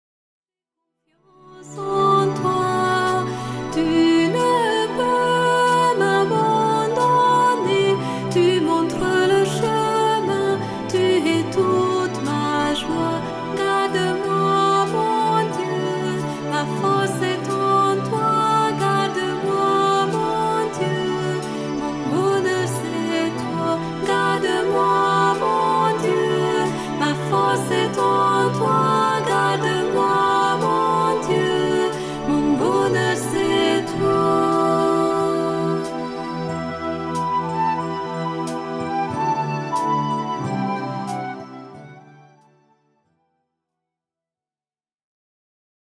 MIDI 4 voix